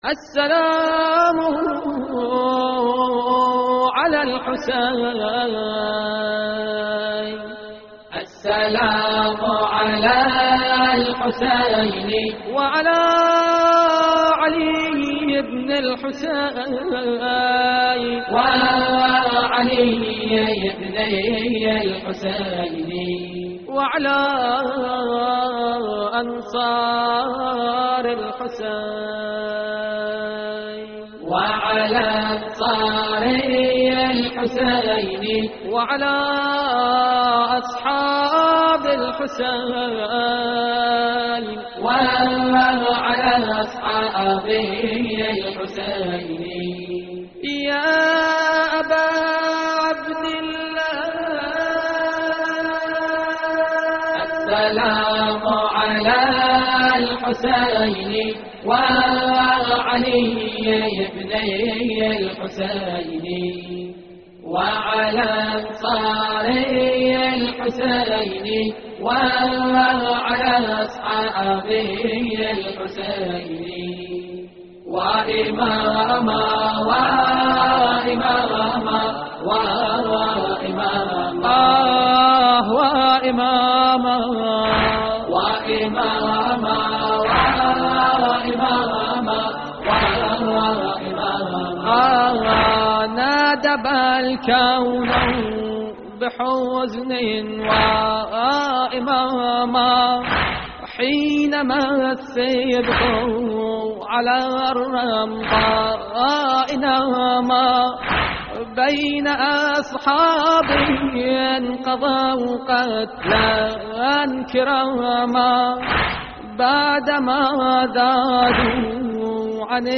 تحميل : السلام على الحسين وعلي ابن الحسين / الرادود نزار القطري / اللطميات الحسينية / موقع يا حسين
موقع يا حسين : اللطميات الحسينية السلام على الحسين وعلي ابن الحسين - استديو لحفظ الملف في مجلد خاص اضغط بالزر الأيمن هنا ثم اختر (حفظ الهدف باسم - Save Target As) واختر المكان المناسب